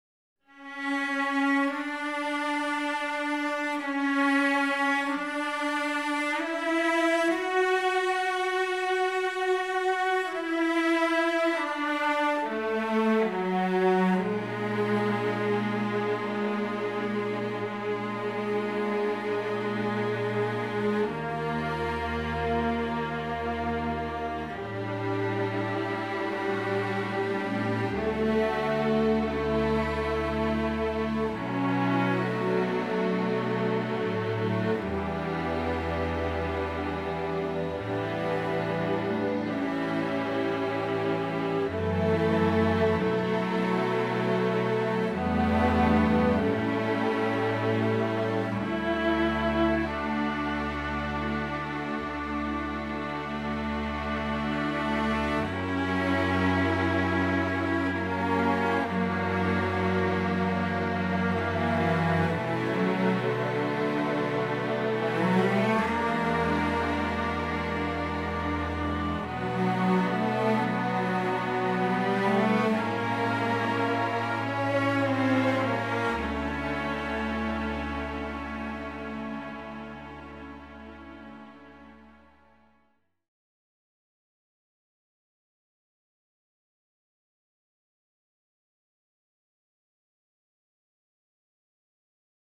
79 - Cellos and violas (stereo - starting with cellos only panned right, joined by violas panned left).wav